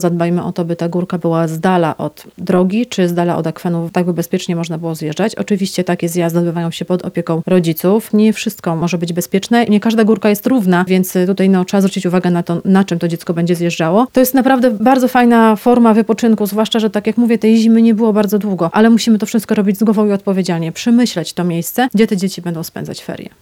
Wypoczynek z głową. Policjantka o bezpiecznych feriach
w porannej rozmowie Radia Lublin